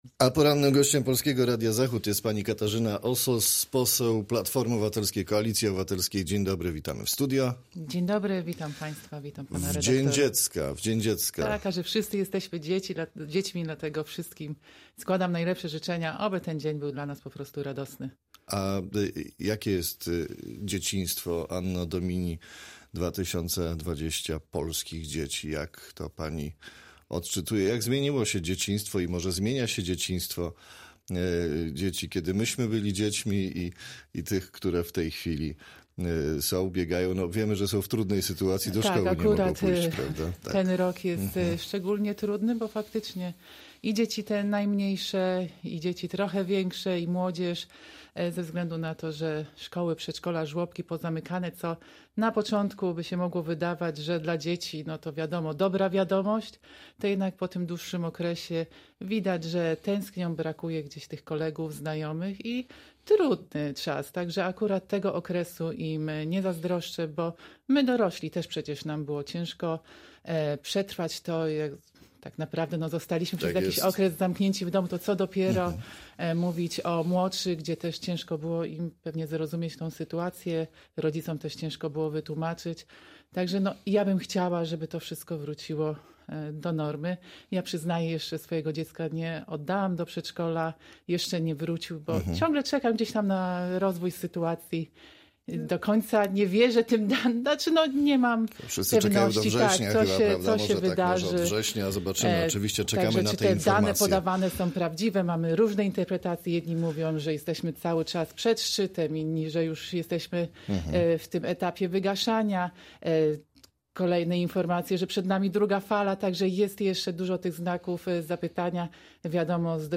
Z poseł PO KO rozmawia